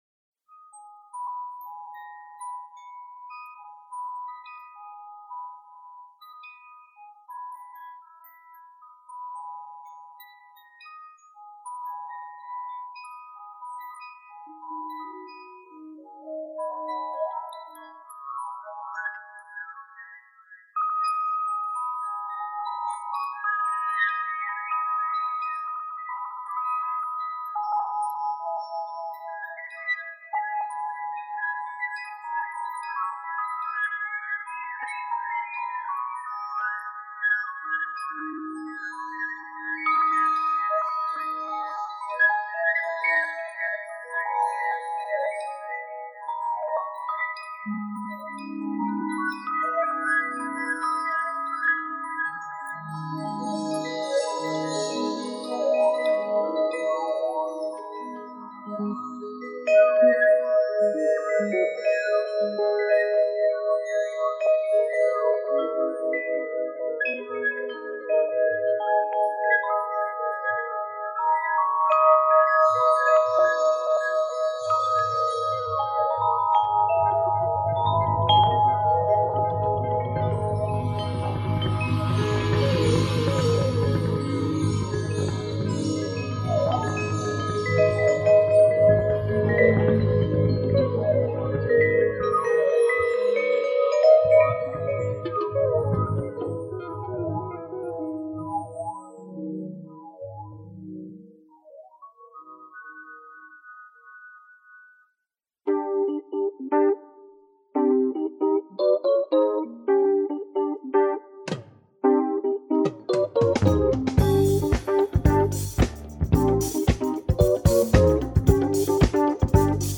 vibraphonist
Includes some jazz funk numbers with breaks
beautiful latin jazz